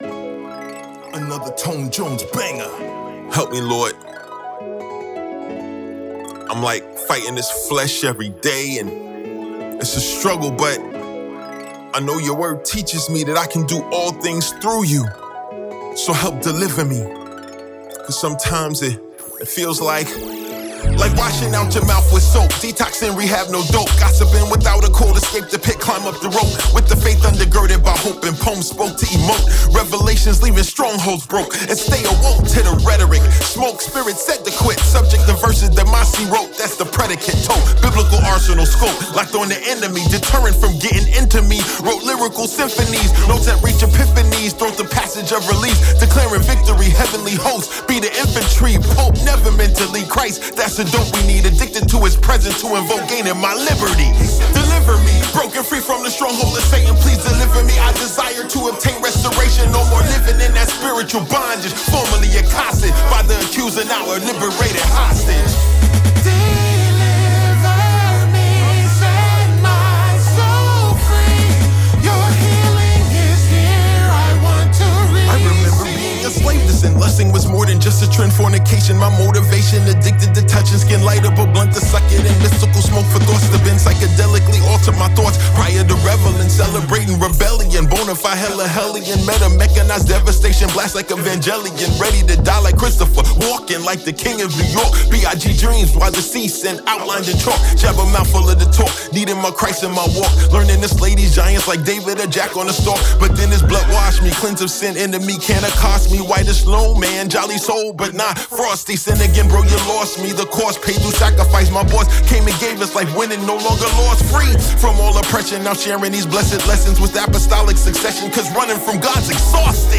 Hip-Hop, Pop, EDM & R&P with the Gospel of Jesus Christ.
CHH (Christian Hip Hop)